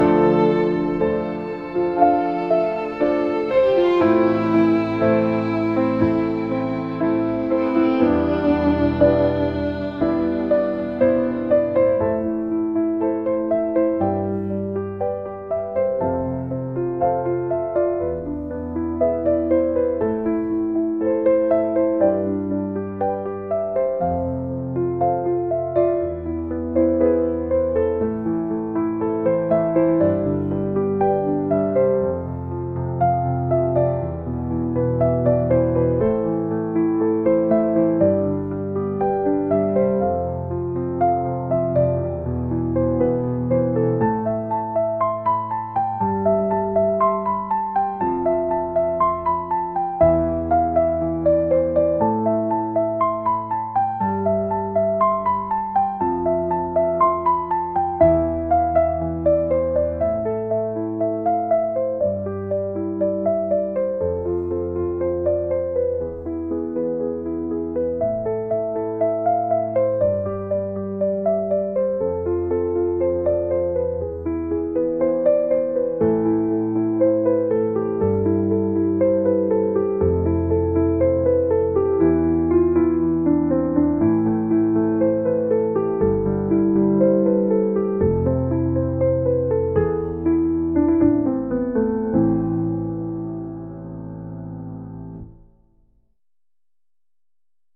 真実が明らかになって切ないという雰囲気のピアノとバイオリン曲です。